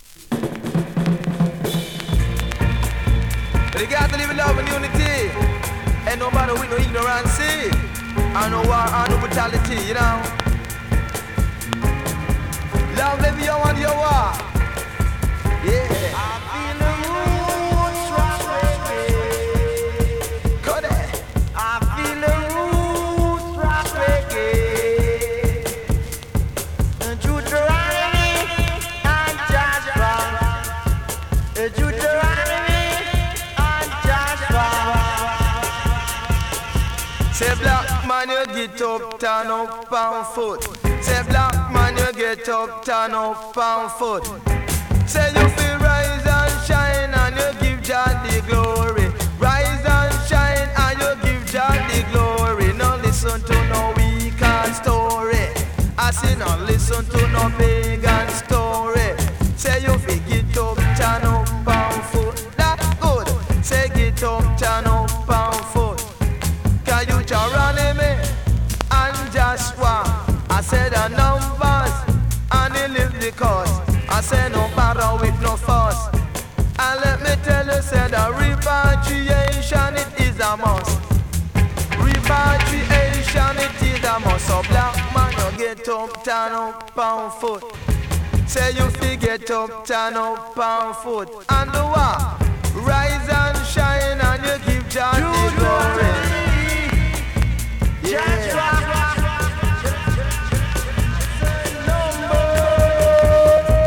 2017 NEW IN!!SKA〜REGGAE!!
スリキズ、ノイズ比較的少なめで